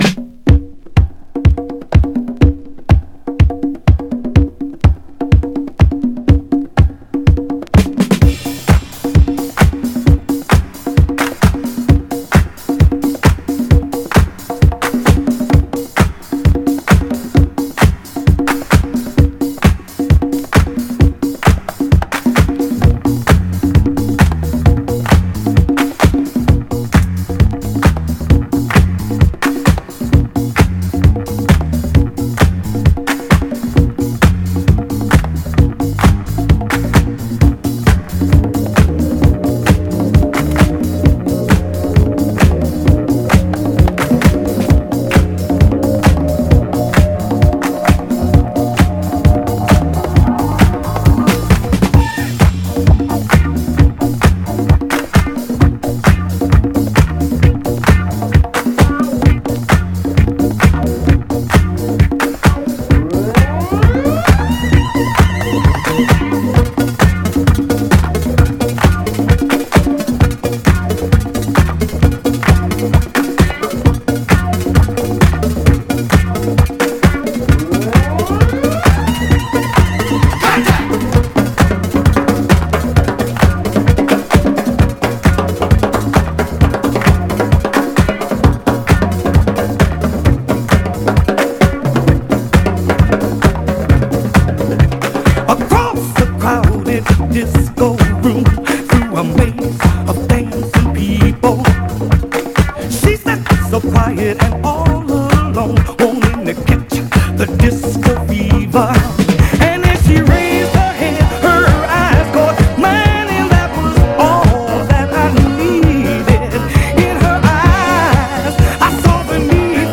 SOUL / SOUL / 70'S～ / FUNK / MEMPHIS SOUL / NORTHERN SOUL